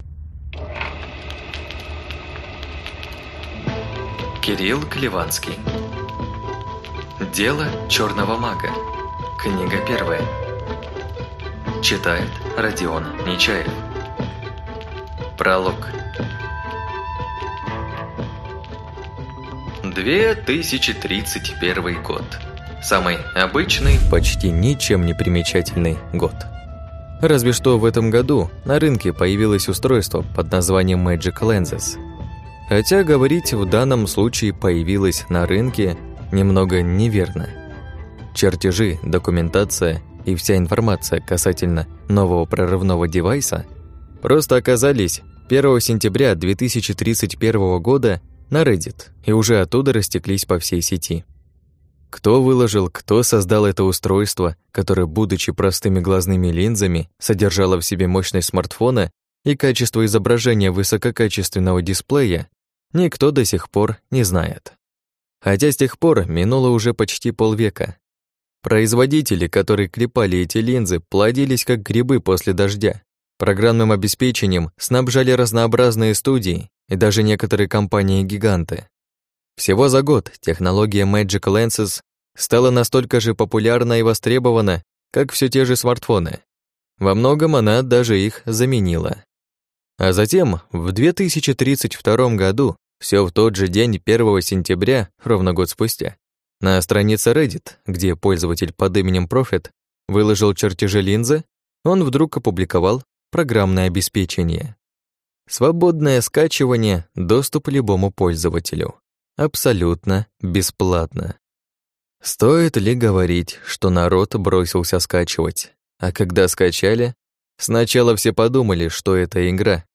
Аудиокнига Дело черного мага. Книга 1 | Библиотека аудиокниг